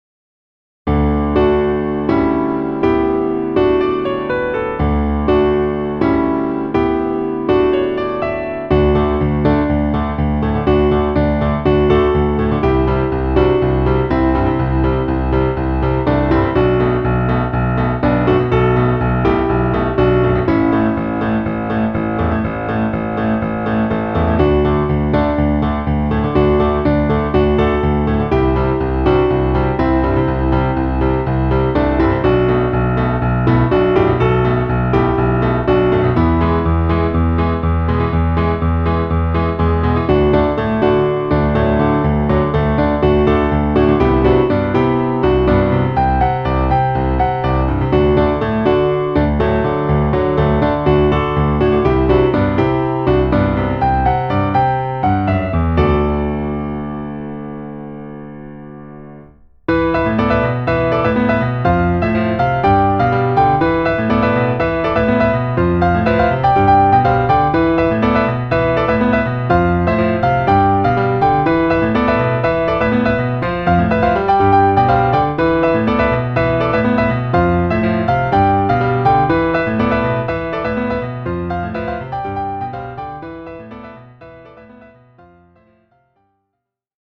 PIANO部屋 新着10曲分・一覧表示は こちら